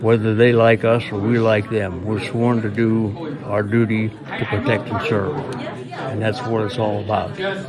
Indiana’s law enforcements officials were recognized Friday afternoon at the George E. Hood Municipal building.
Sheriff Bob Fyock said that while it’s always nice to be recognized, it’s all about doing the job they swore to do.